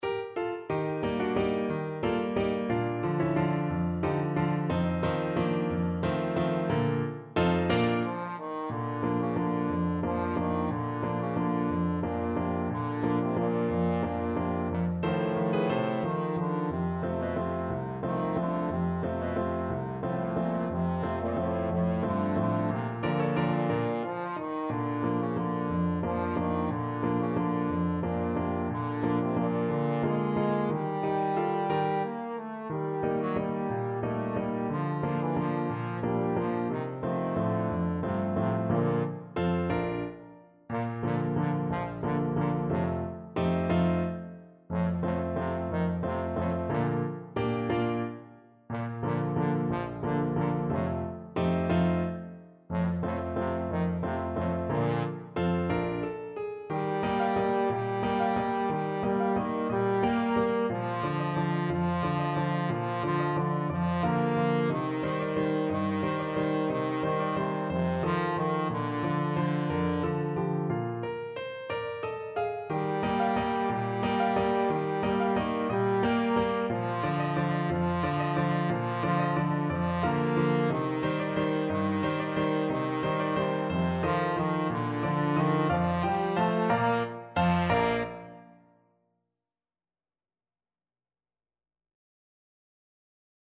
Traditional Music of unknown author.
One in a bar .=c.60
3/4 (View more 3/4 Music)
A3-Bb4